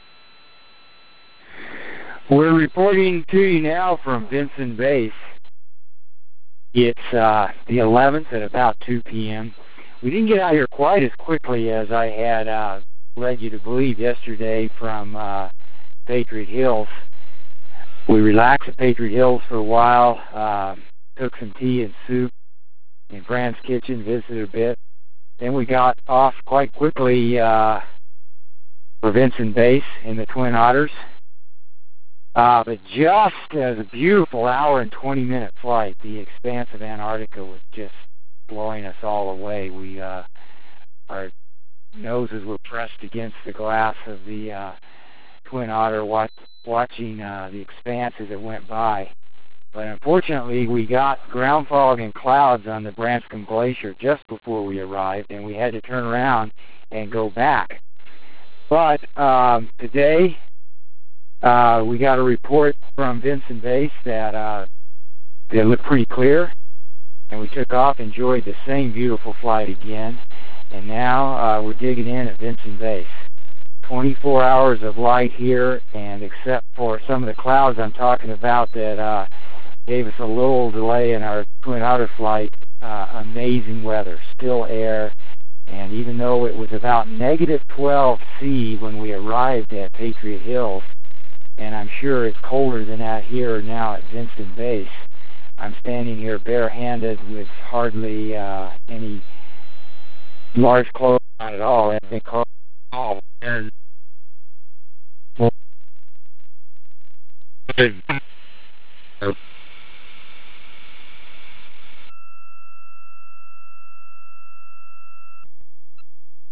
December 11 – Arrival at Vinson Base